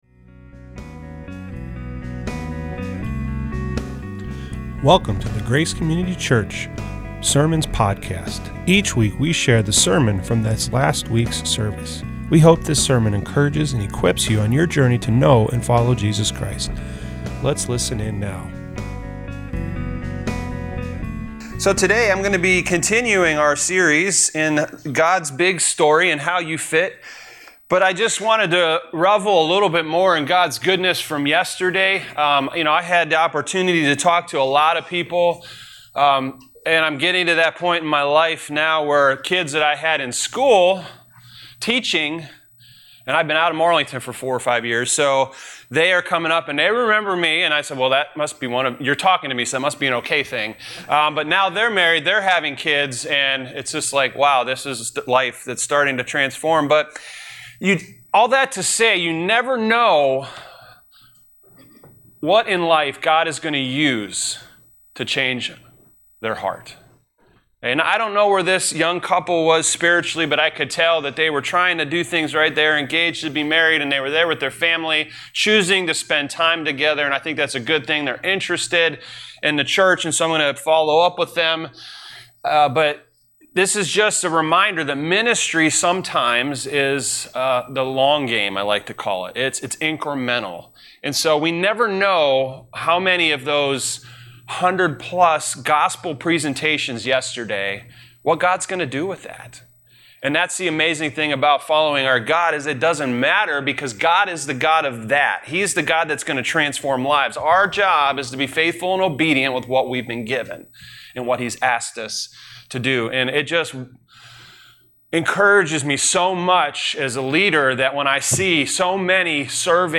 This week we continued our new series titled ‘God’s big story, and how YOU fit!’ The title of this message is “No king but God". Primary scripture passages are 2 Kings 17-19.